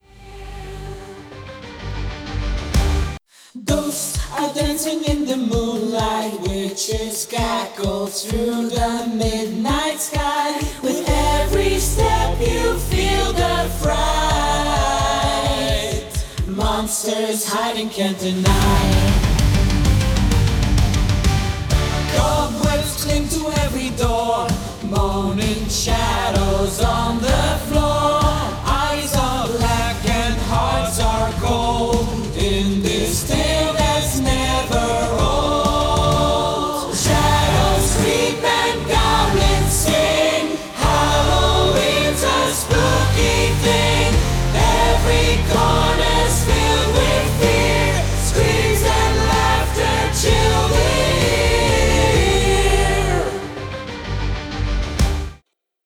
Alle Stemmen